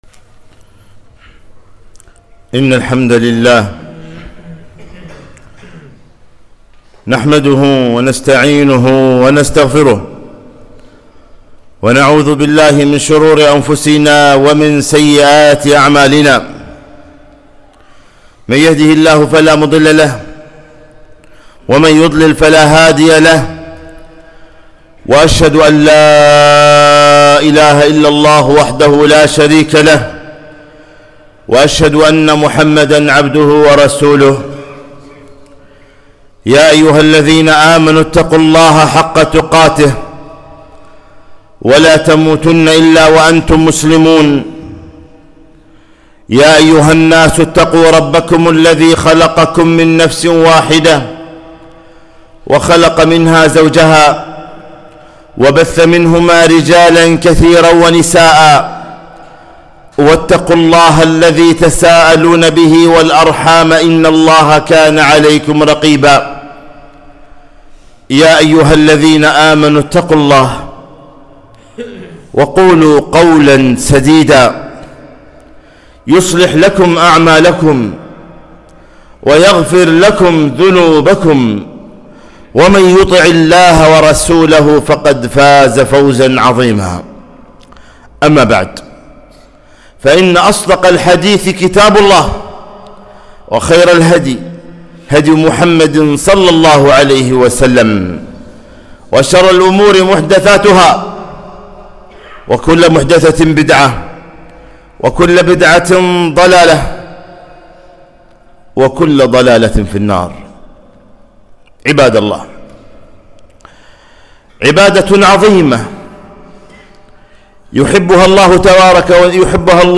خطبة - تعظيم الله تعالى